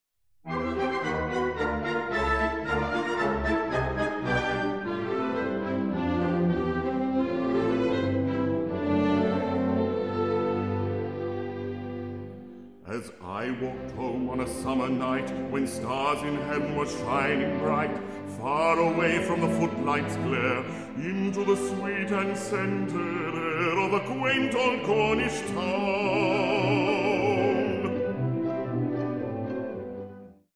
Bass Baritone